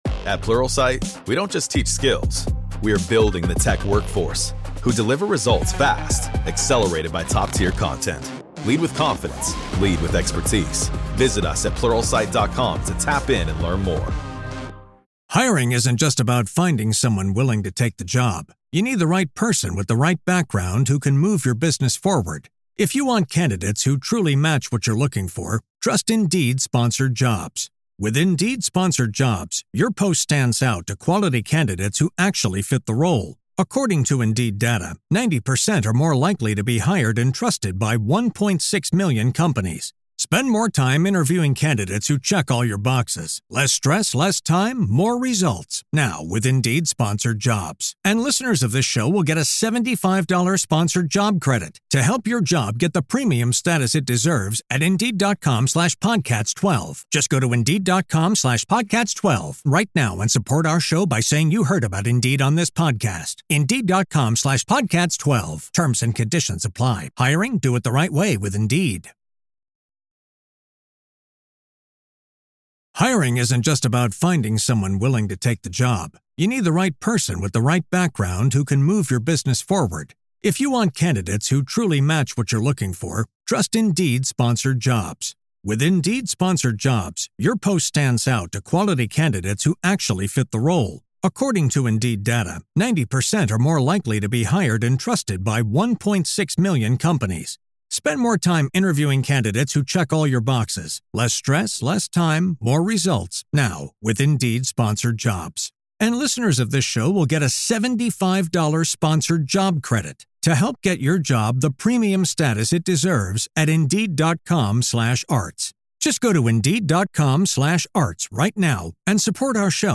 PART 2 – AVAILABLE TO GRAVE KEEPERS ONLY – LISTEN HERE In part two of our interview, available only to Grave Keepers, we discuss: Was Dr. Snead involved in questionable medical practices, or is he more insulted by the accusation after death?